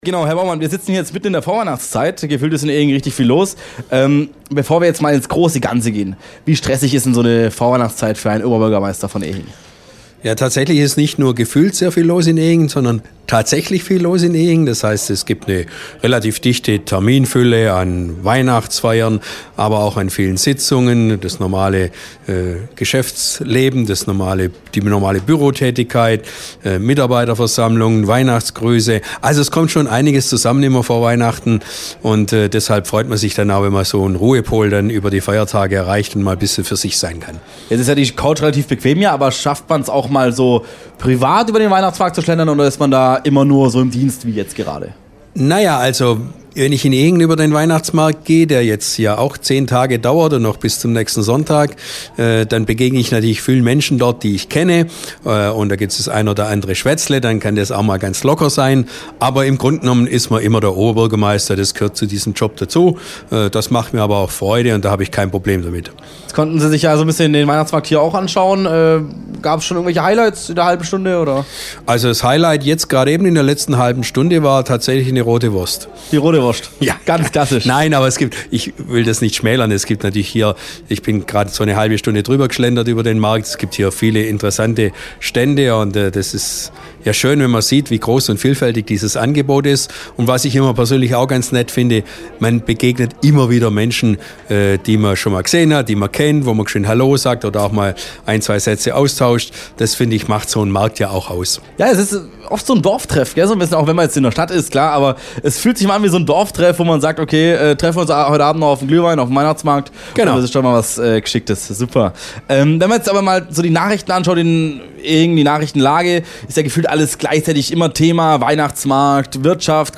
Das DONAU 3 FM Weihnachtsmarktradio mit Alexander Baumann, Oberbürgermeister von Ehingen ~ Ulmer Weihnachtsmarkt-Podcast Podcast